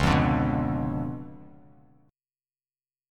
C#7b9 chord